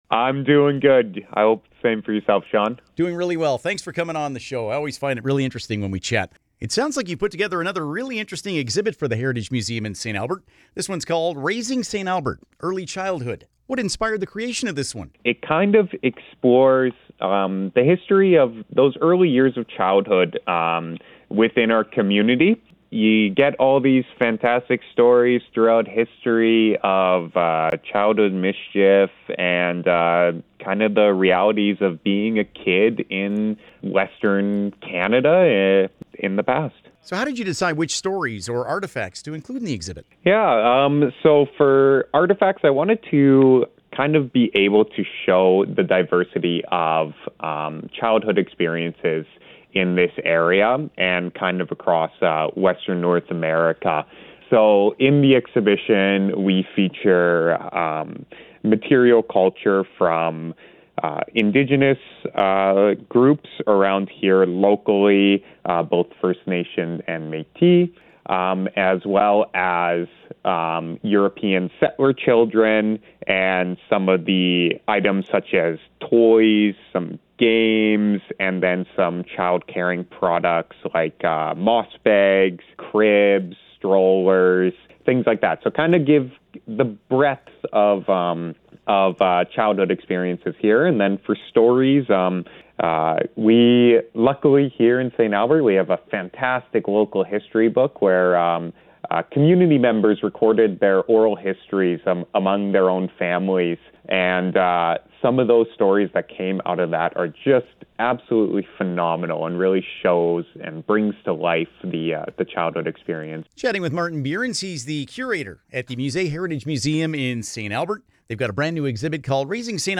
raising-st-albert-interview.mp3